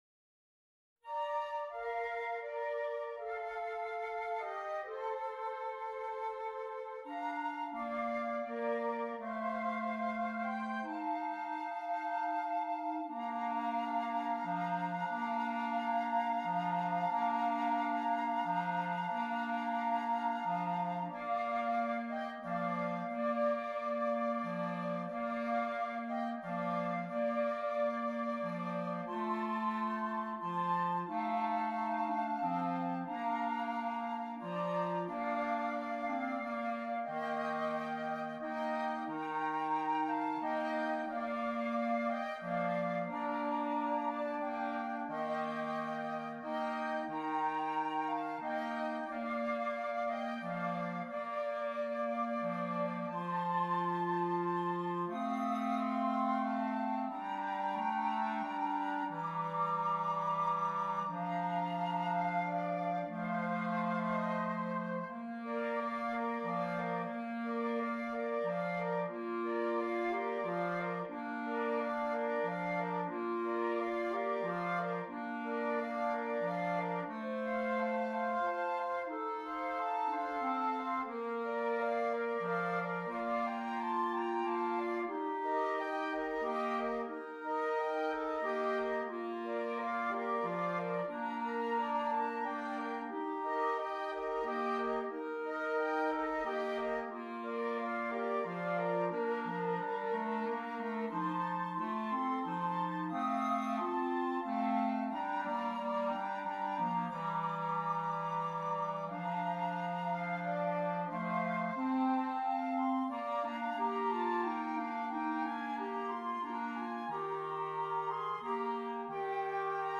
Christmas
2 Flutes, 2 Clarinets